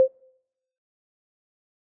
point_score_2.wav